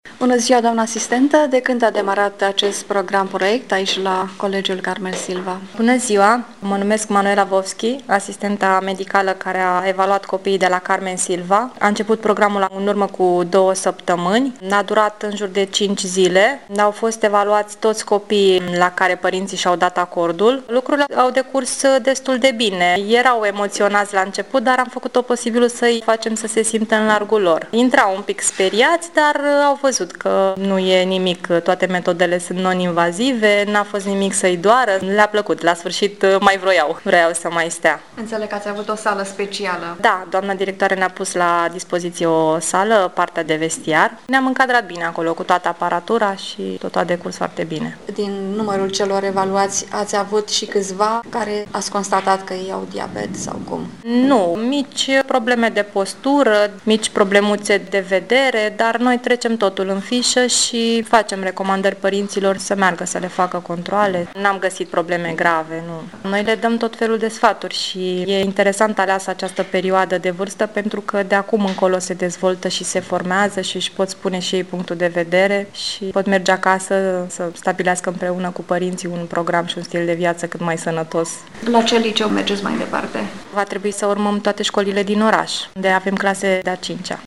Reportaje și interviuri radio difuzate la Radio SOS Prahova, în data de 14 noiembrie 2017, cu ocazia Zilei Mondiale a Diabetului.
Interviu